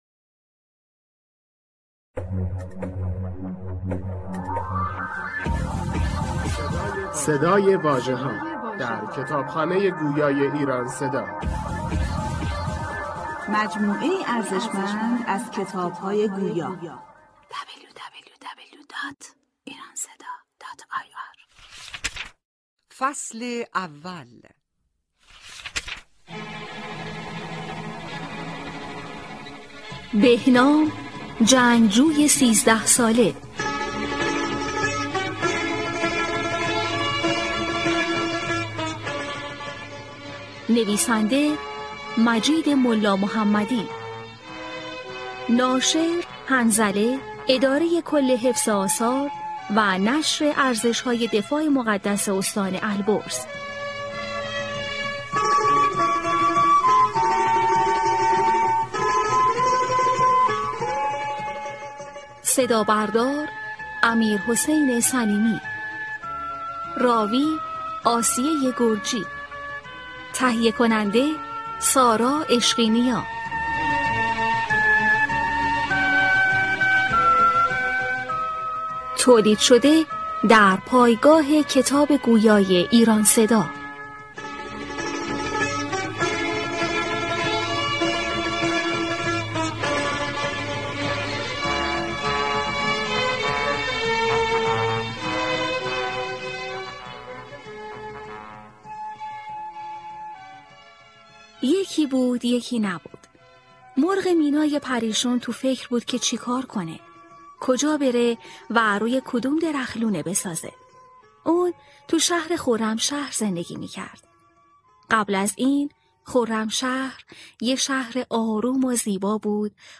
کتاب صوتی بهنام جنگجوی 13 ساله